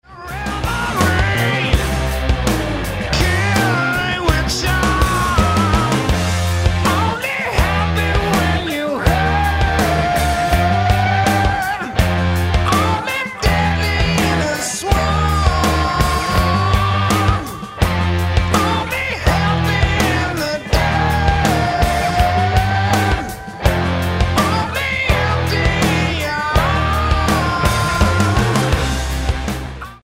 guitar
drums
bass